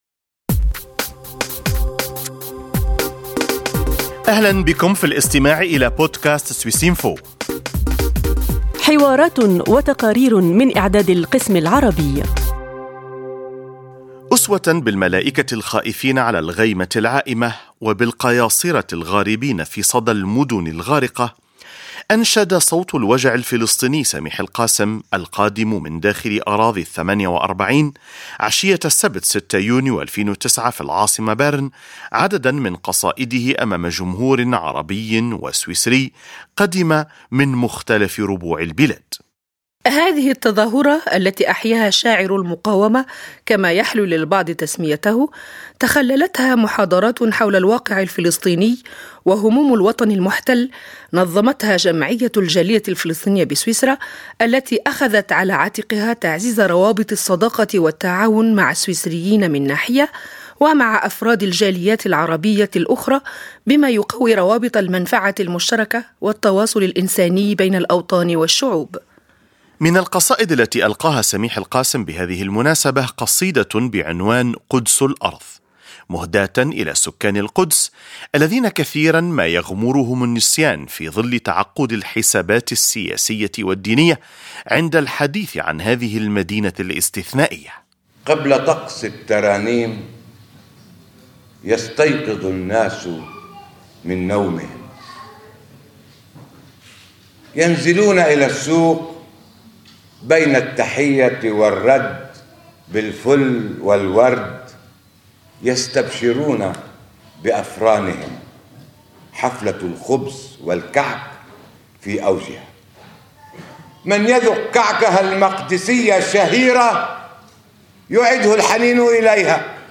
عشية 6 يونيو 2009، أنشد صوت الوجع الفلسطيني القادمُ من داخل أراضي 48، بعض إبداعاته الشعرية أمام جمهور عربي وسويسري، تحول خصيصا للإستماع إليه من مختلف ربوع البلاد.